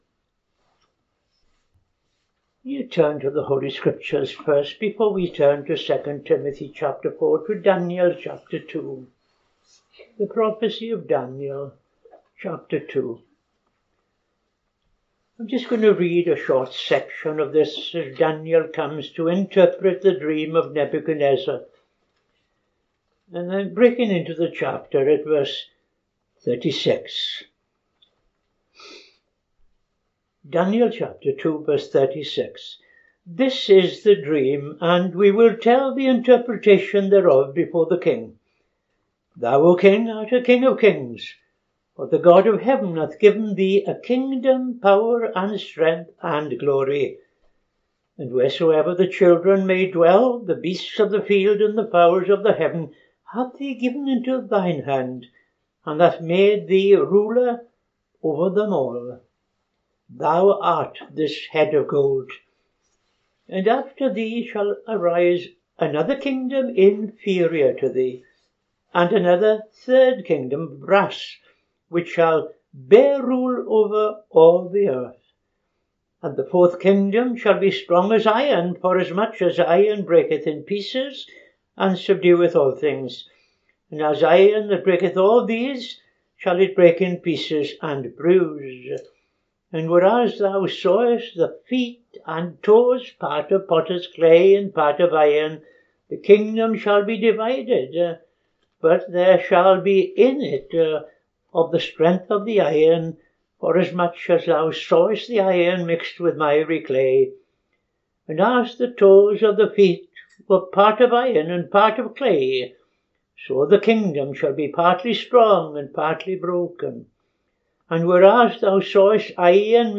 Reading Daniel 2:36-45; II Timothy 4:18